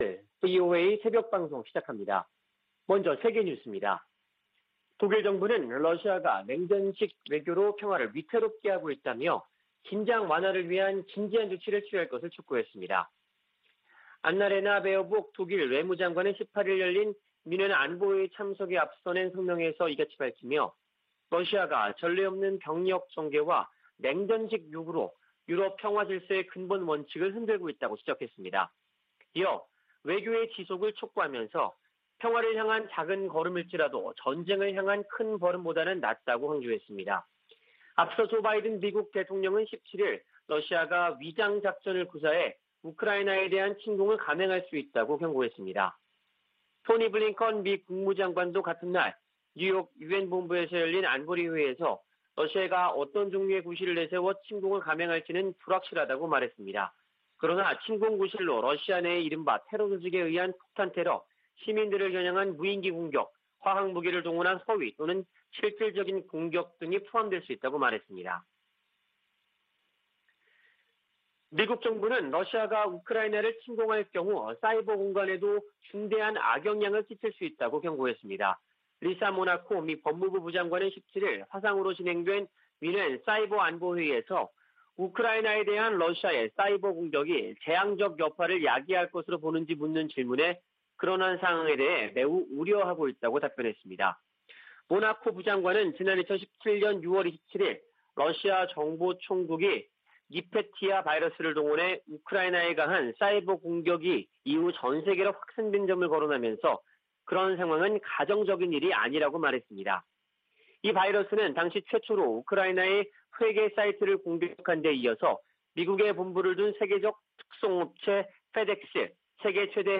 VOA 한국어 '출발 뉴스 쇼', 2021년 2월 19일 방송입니다. 미 국무부 동아태 차관보가 미국, 한국, 일본의 최우선 과제로 북한 핵·미사일 위협 대응을 꼽았습니다. 해리 해리스 전 주한 미국대사는 대화를 위해 북한 위협 대응 능력을 희생하면 안된다고 강조했습니다. 북한이 가상화폐 자금에 고도화된 세탁 수법을 이용하고 있지만 단속이 불가능한 것은 아니라고 전문가들이 말했습니다.